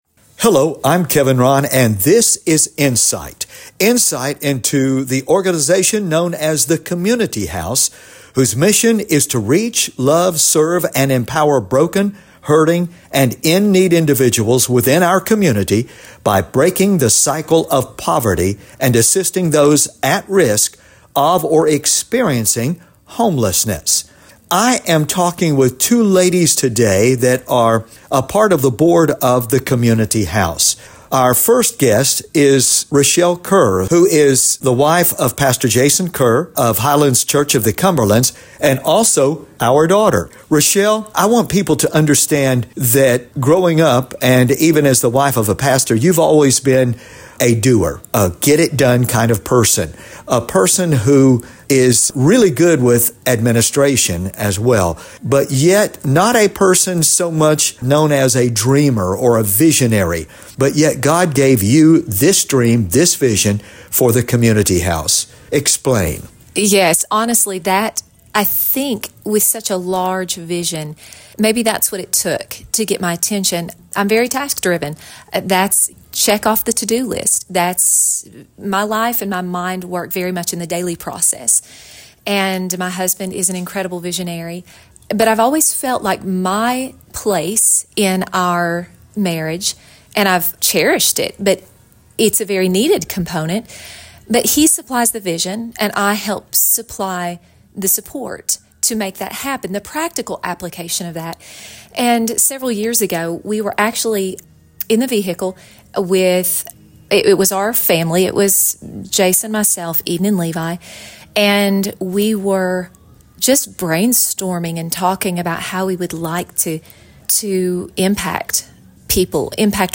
Listen to Interview on The Vision of The Community House
Listen to this 27-minute interview that was aired on WDEB-Radio inJamestown. It explains the origin, purpose, and vision for The Community House.